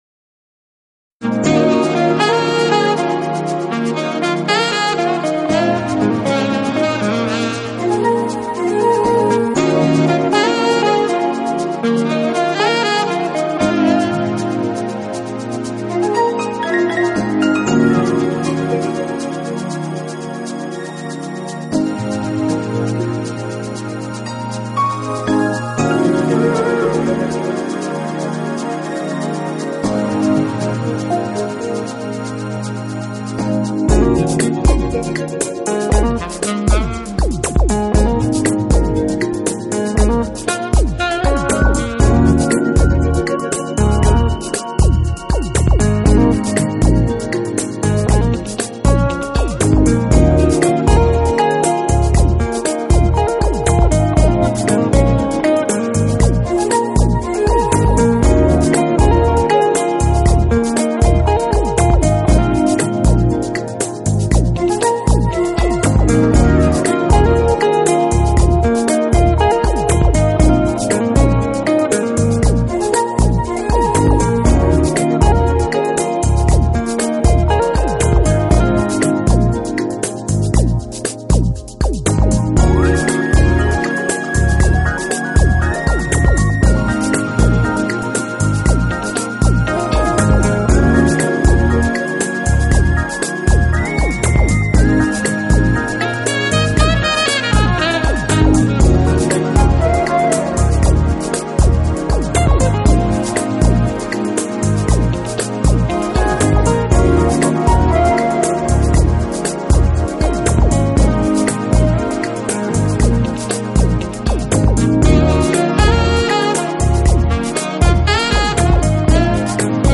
旋律轻柔流畅，器乐创新搭配，节奏舒缓时尚，魅力